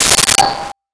Sword Hit 01
Sword Hit 01.wav